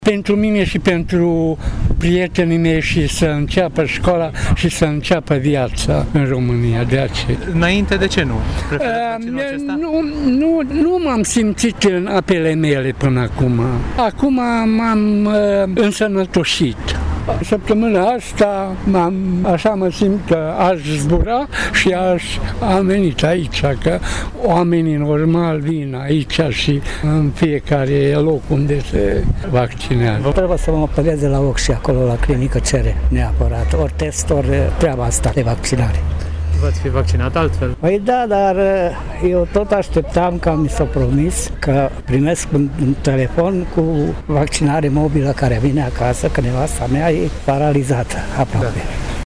Dovadă că la cortul din Piaţa Teatrului nu s-au prezentat doar tineri dornici să intre la festival, ci şi seniori care spun că este momentul potrivit pentru vaccinare, mai ales că se apropie toamna: